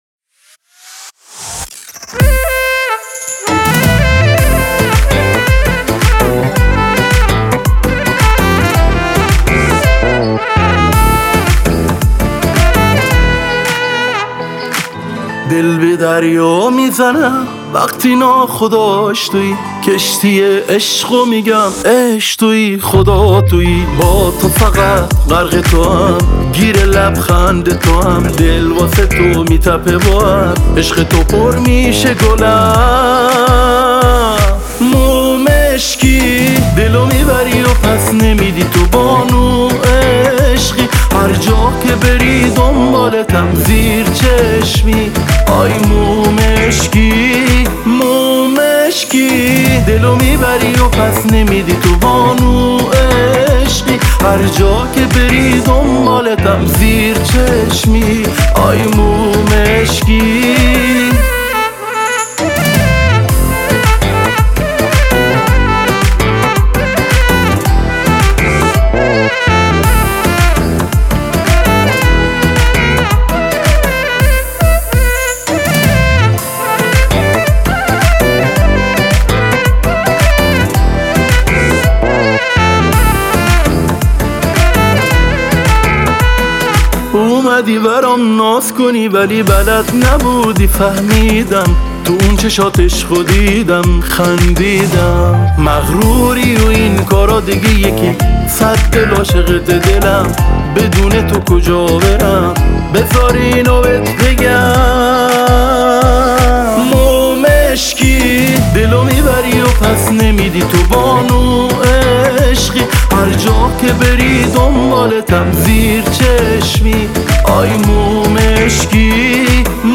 بندری